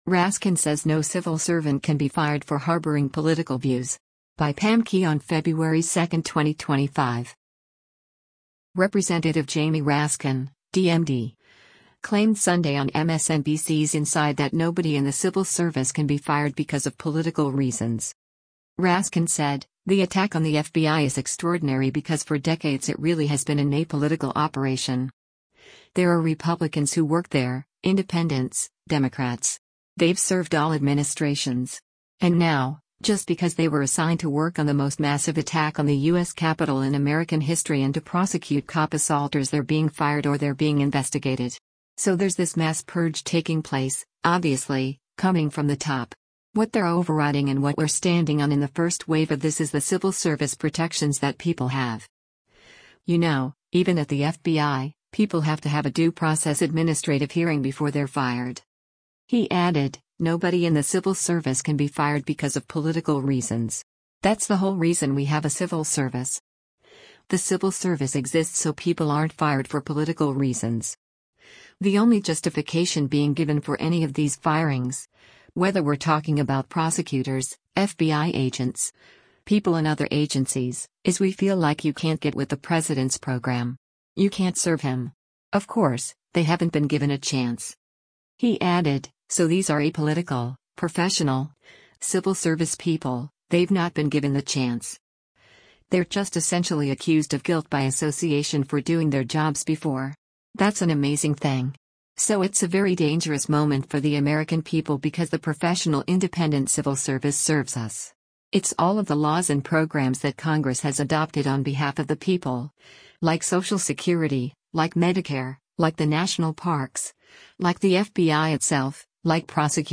Representative Jamie Raskin (D-MD) claimed Sunday on MSNBC’s “Inside” that “nobody in the civil service can be fired because of political reasons.”